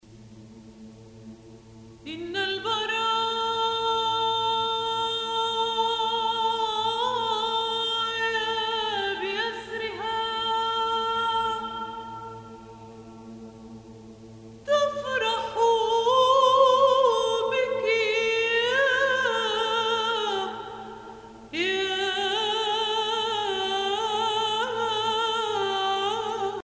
circonstance : dévotion, religion
Pièce musicale éditée